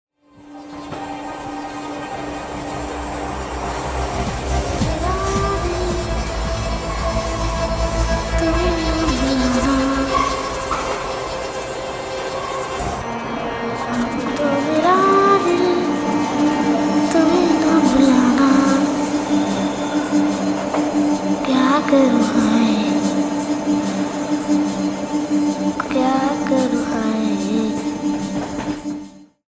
Voice:  Bengali child singer